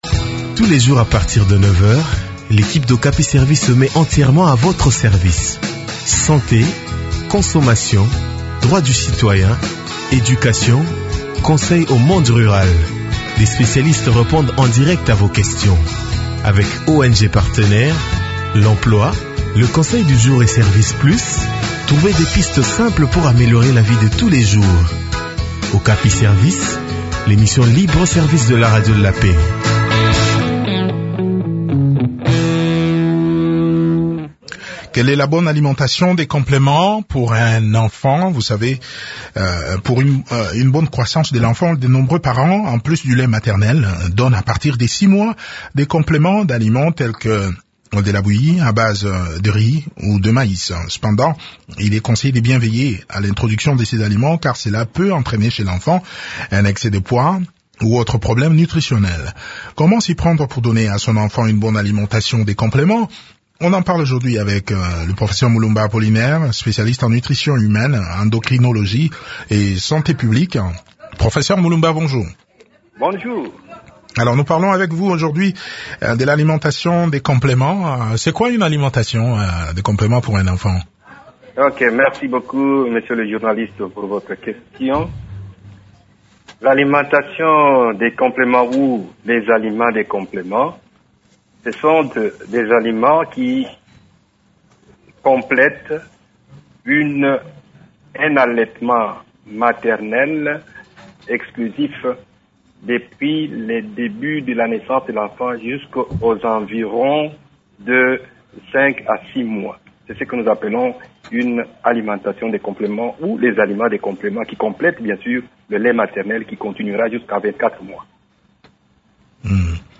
répond aux questions des auditeurs